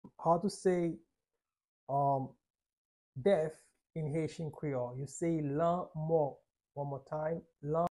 How to say "Death" in Haitian Creole - "Lanmò" pronunciation by a native Haitian teacher
How-to-say-Death-in-Haitian-Creole-Lanmo-pronunciation-by-a-native-Haitian-teacher.mp3